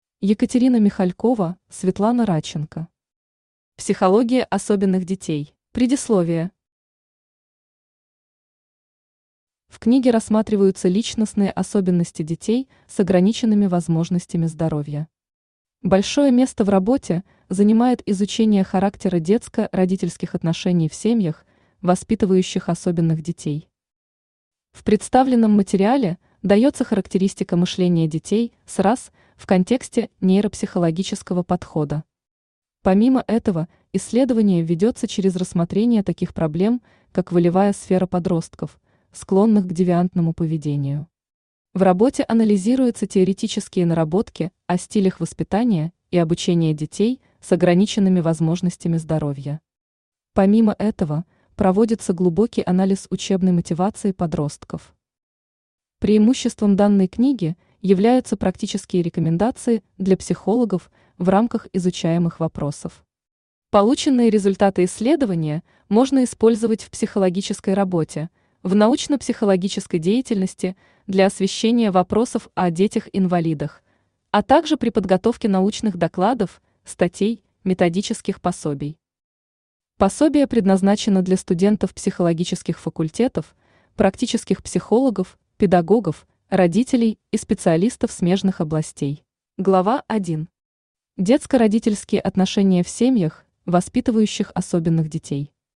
Аудиокнига Психология особенных детей | Библиотека аудиокниг
Aудиокнига Психология особенных детей Автор Екатерина Ивановна Михалькова Читает аудиокнигу Авточтец ЛитРес.